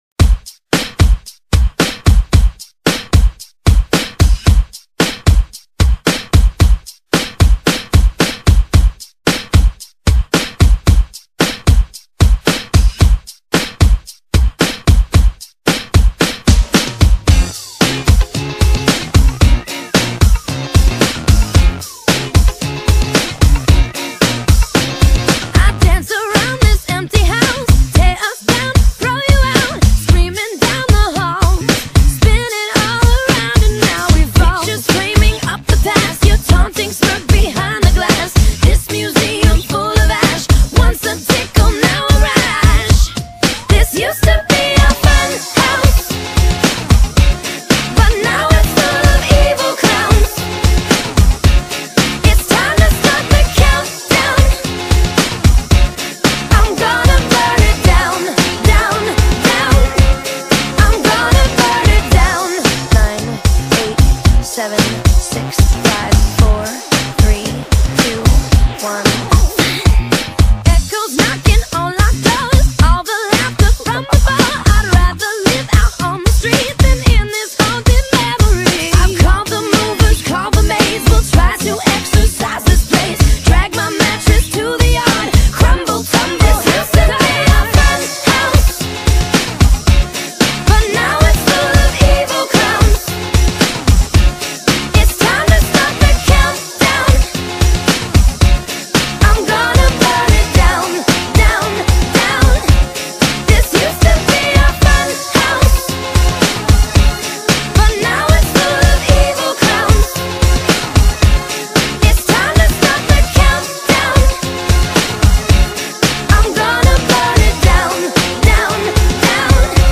Club Music
ClubMix